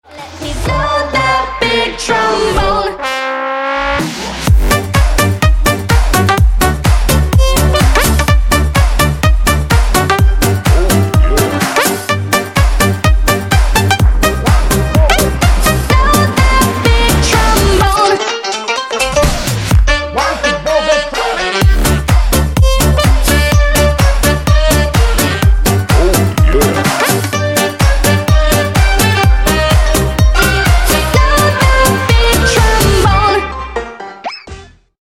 Громкие Рингтоны С Басами » # Весёлые Рингтоны
Танцевальные Рингтоны